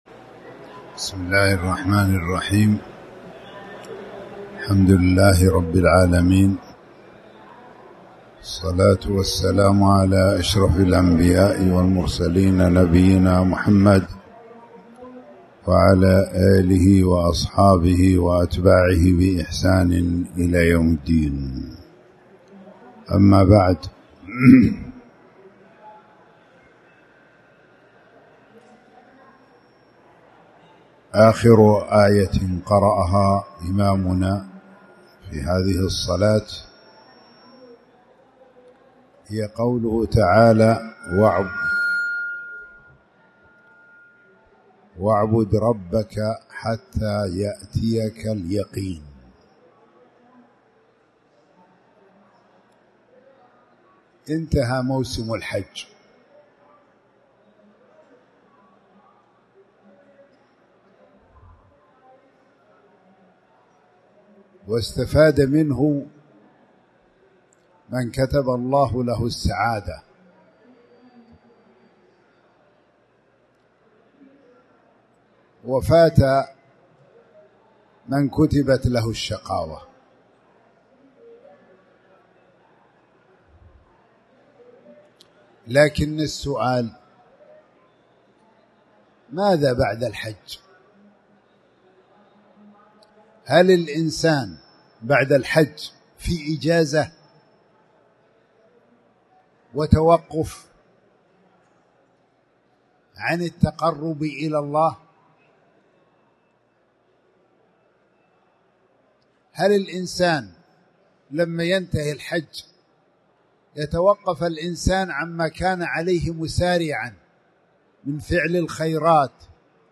تاريخ النشر ٢٢ ذو الحجة ١٤٣٨ المكان: المسجد الحرام الشيخ